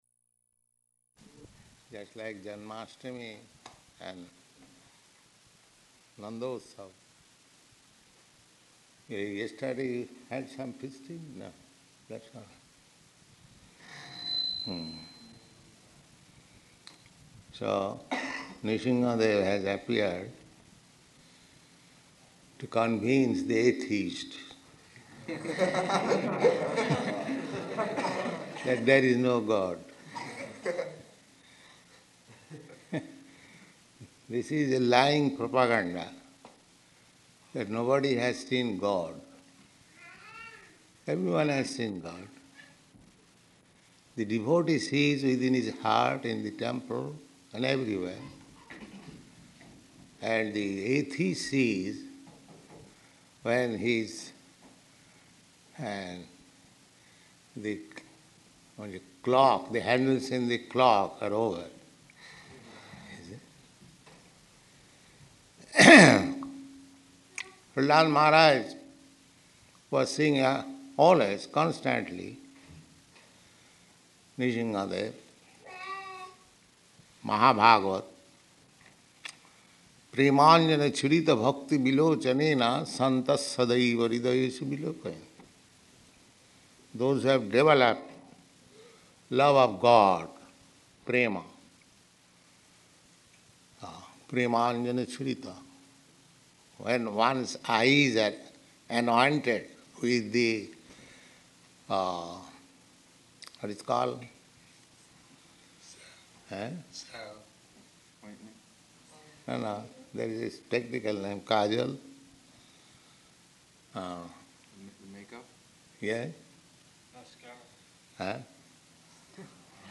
Location: Boston